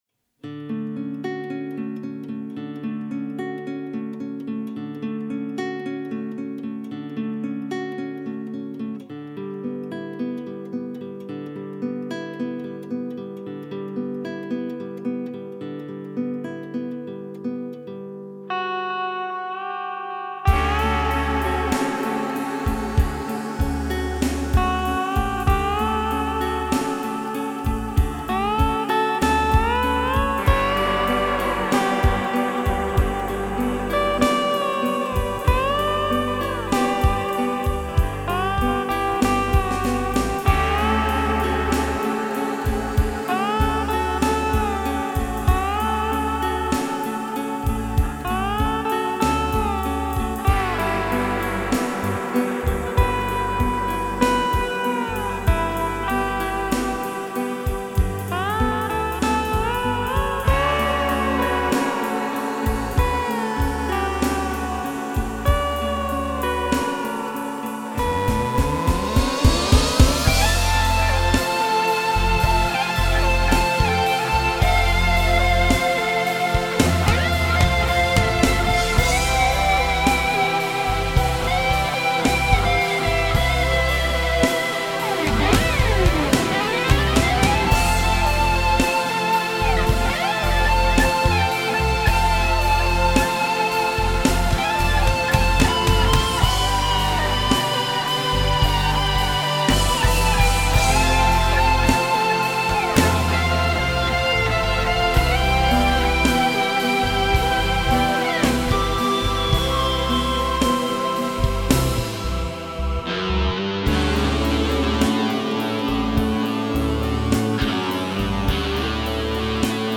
Voici donc un morceau assez « Floydien ».
S 760, JD 800, 01/W, ARP 2600, S 2000, Microkorg, Nostalgia VST, SPD 20, Guitare acoustique, Fender Stratocaster, Fender Jazz Bass.